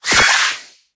Cri de Lézargus dans Pokémon HOME.